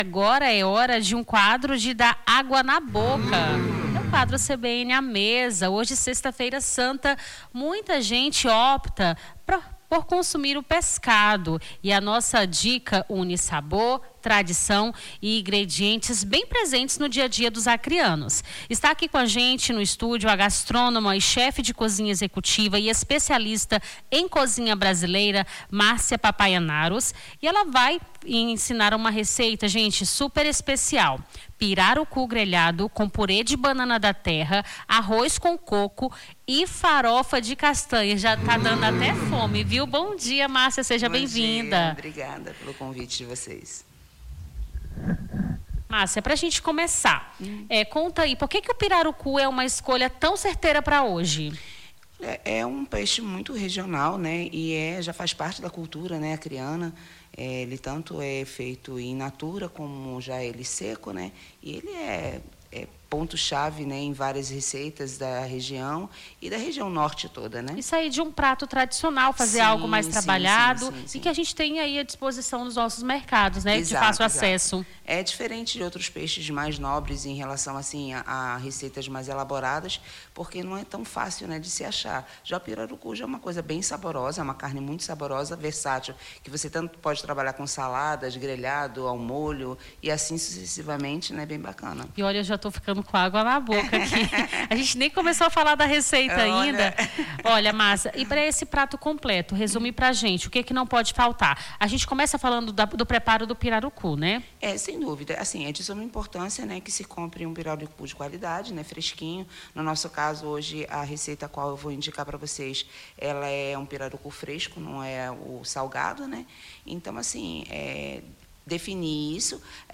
CBN à Mesa: chefe de cozinha ensina como fazer pirarucu grelhado com purê de banana da terra, arroz com coco e farofa de castanha